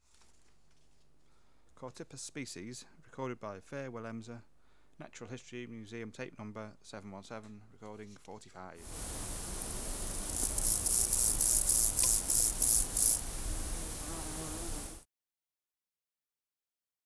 Natural History Museum Sound Archive Species: Chorthippus